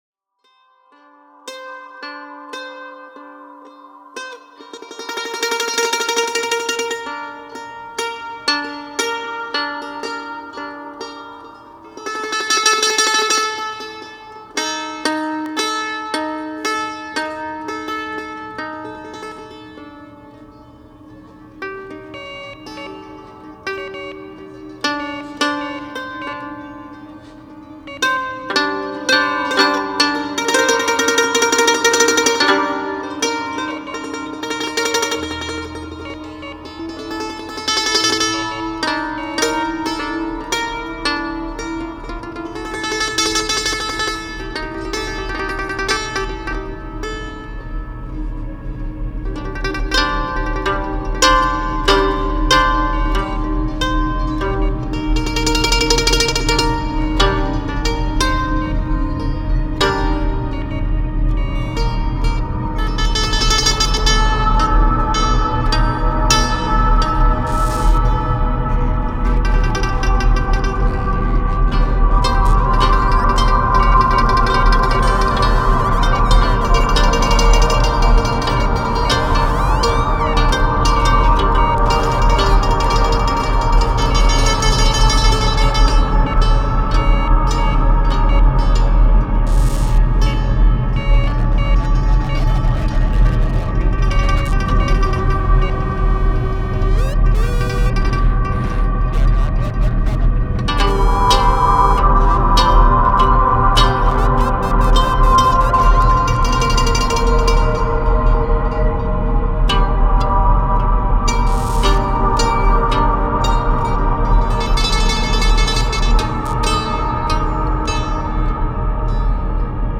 folk-rock band